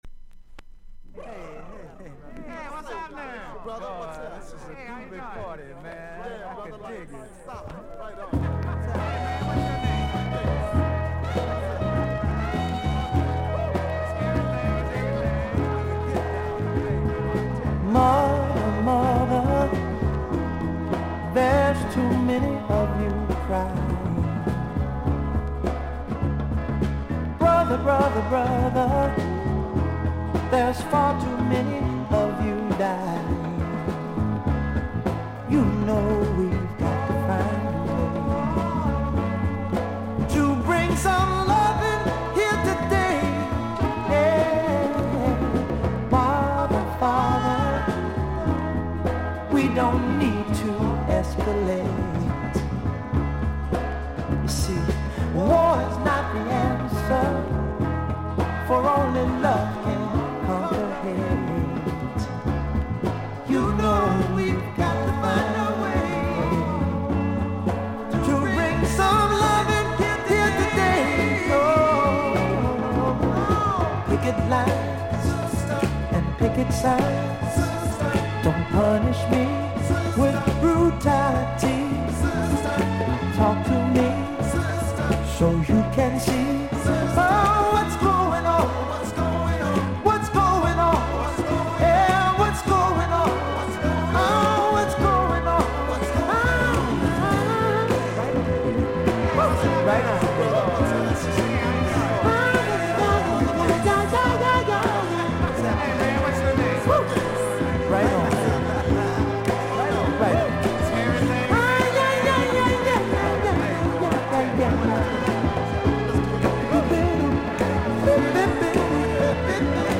音のグレードは見た目より良くVG+〜VG++:少々軽いパチノイズの箇所あり。少々サーフィス・ノイズあり。クリアな音です。
R&B/ソウル・シンガー。